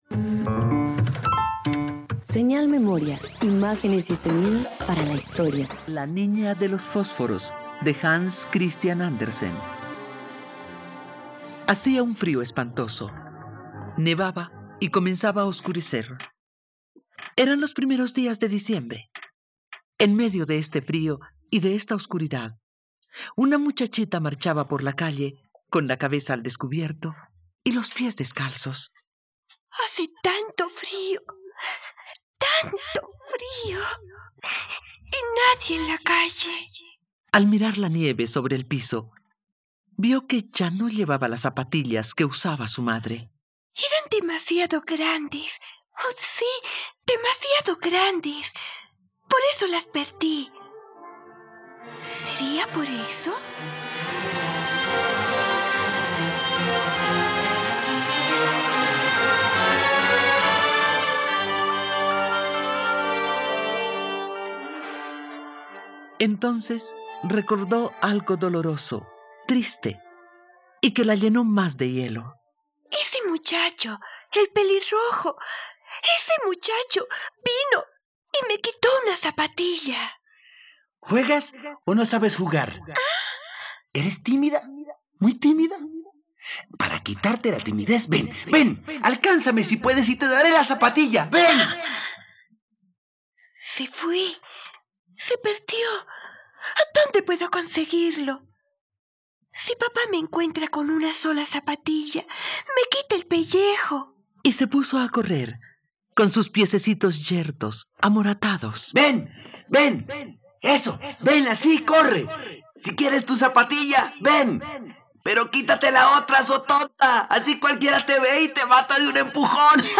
La niña de los fósforos - Radioteatro dominical | RTVCPlay